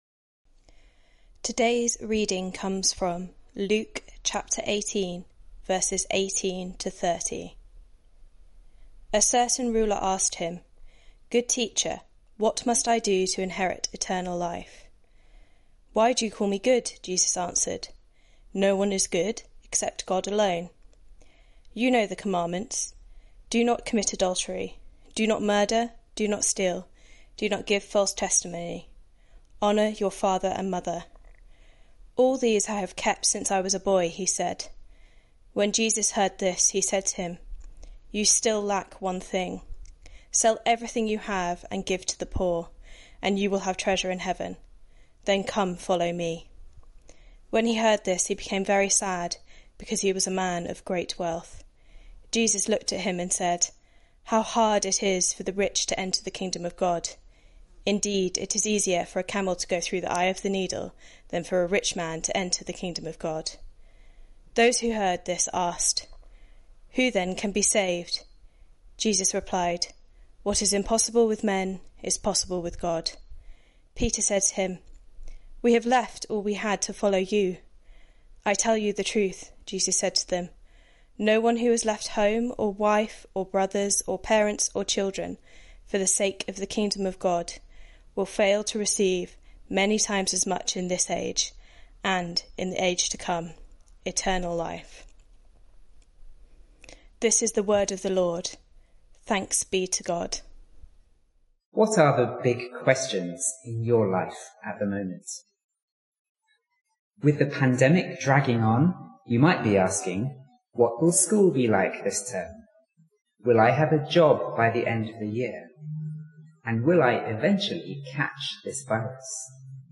Theme: ...a rich ruler Online Service Talk (Audio) Search the media library There are recordings here going back several years.